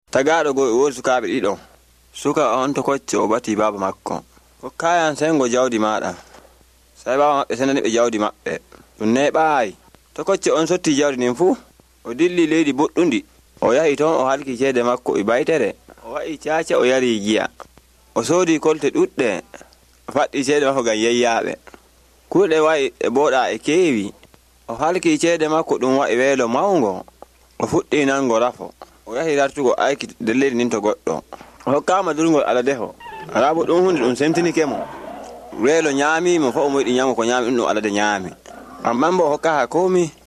On the one hand it sounds somewhat reminiscent of a downstepping West African-type language with pre-glottalised stops that might be a language-specific way of realising implosives.
The recording, with the squealing pigs sound effect toward the cut-off, sounds like it’s a version of the Prodigal Son story from that web site with three or four Bible stories rendered in approximately a million and three different languages.
I hear something that sounds like overall tonal downdrift at the phrasal level, but I’m not sure this is a tonal language, even one with only two level tones.